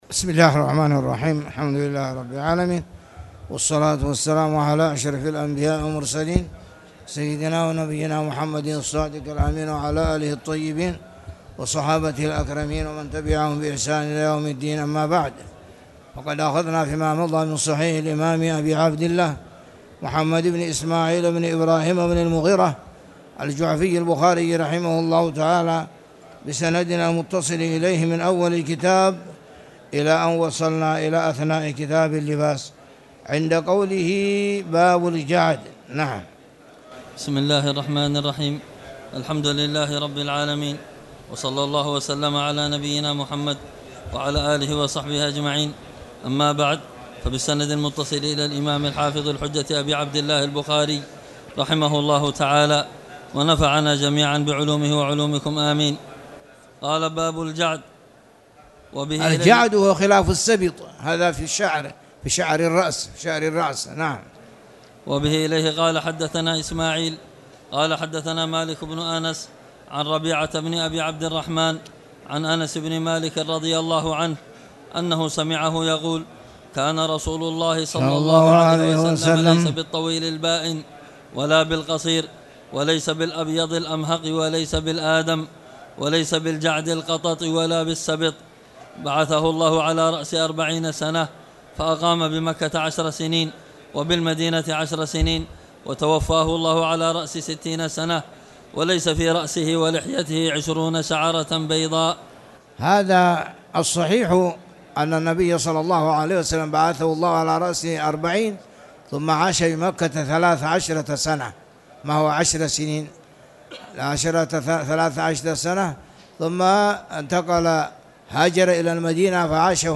تاريخ النشر ٨ جمادى الأولى ١٤٣٨ هـ المكان: المسجد الحرام الشيخ